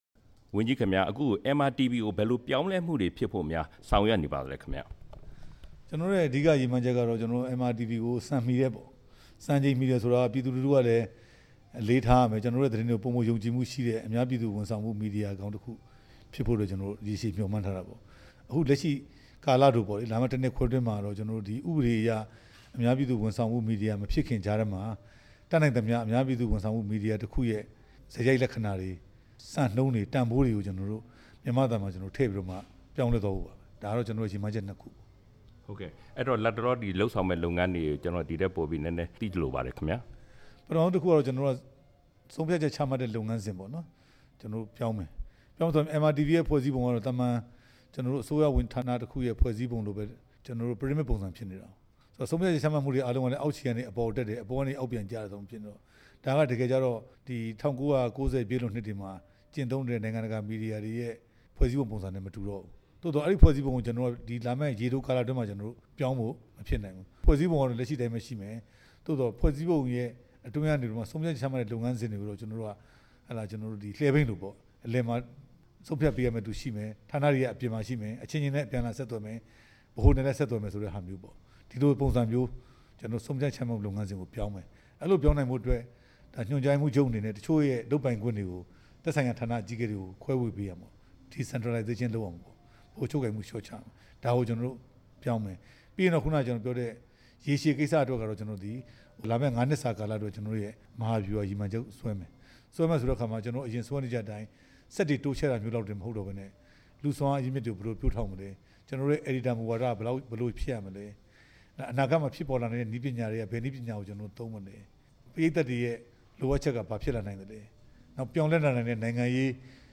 ဝန်ကြီး ဦးရဲထွဋ်နဲ့ မေးမြန်းချက်